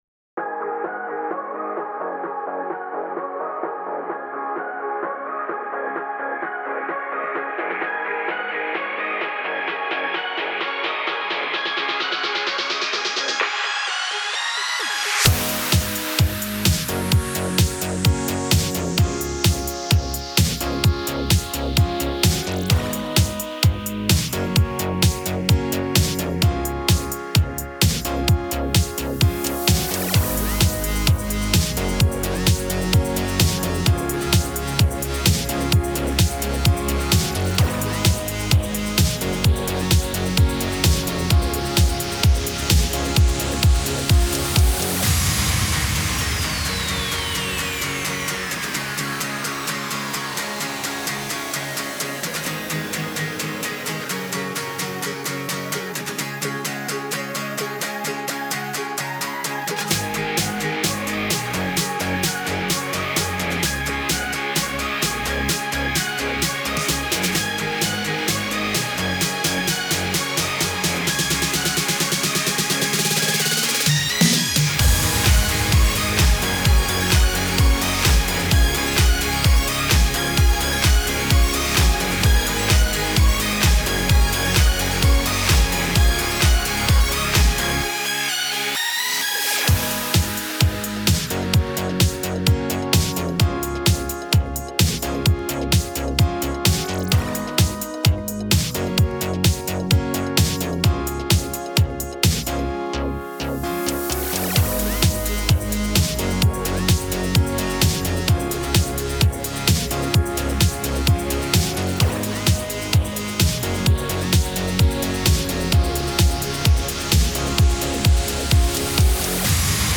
Motivation upbeat ;-)